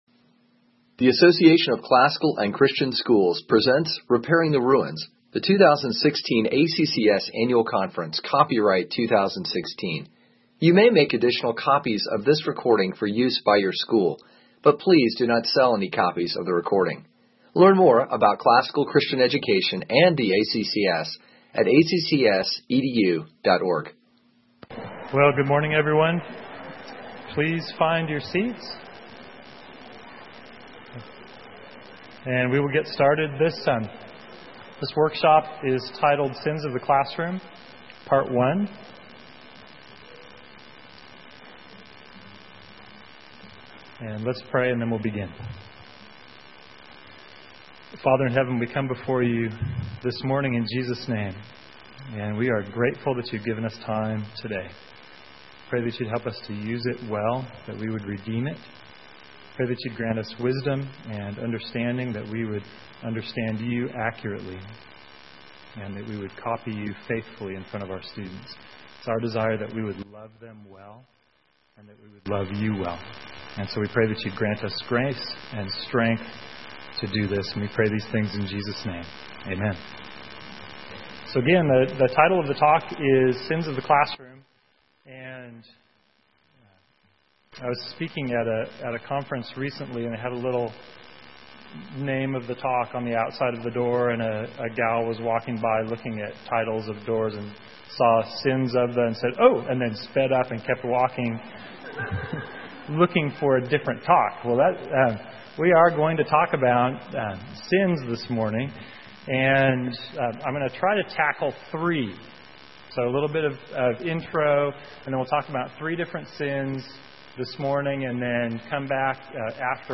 2016 Workshop Talk | 55:39:00 | All Grade Levels, Virtue, Character, Discipline